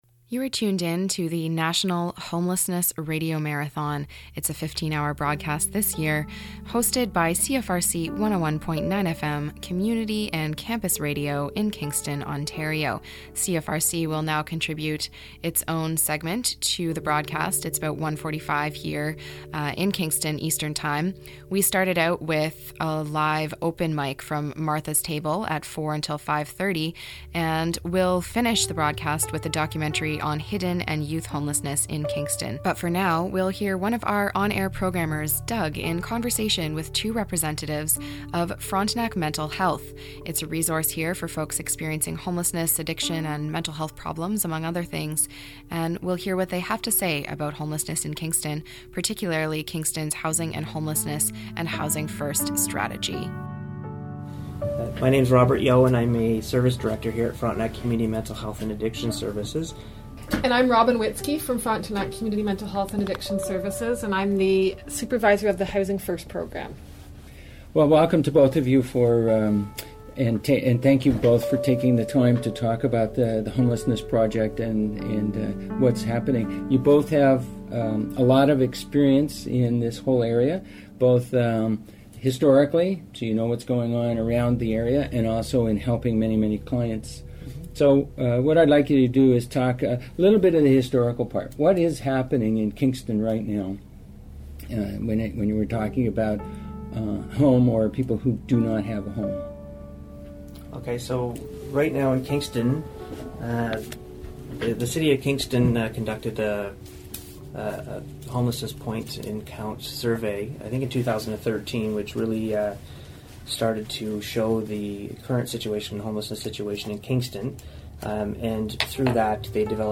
Interviews with Frontenac Mental Health staff in Kingston, Ontario